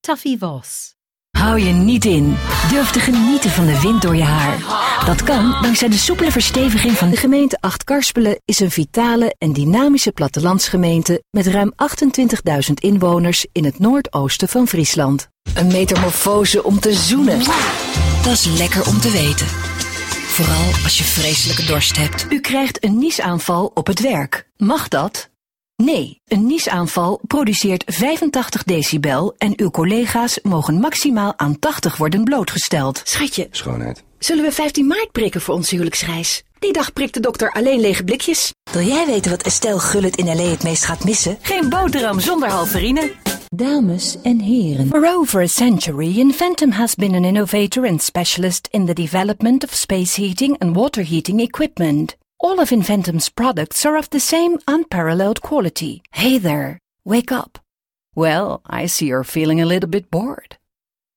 Dutch actor and voiceover artist